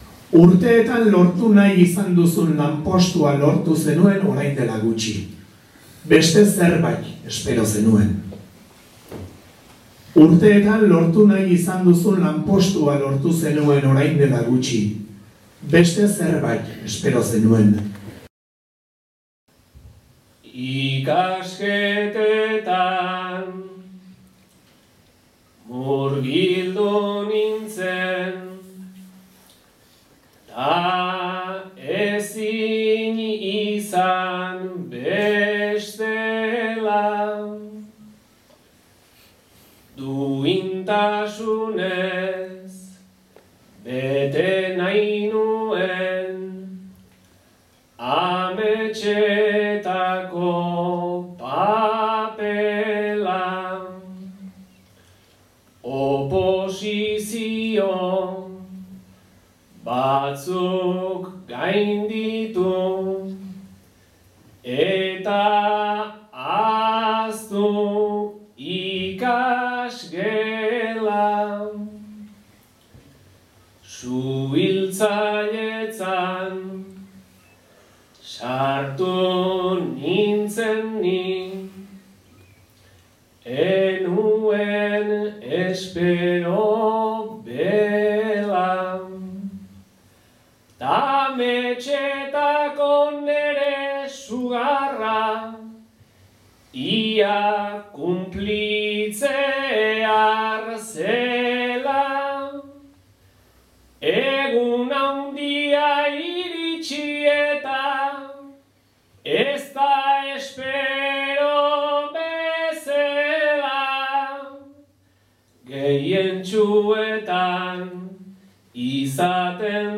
Gipuzkoa bertsotan. Sailkapena.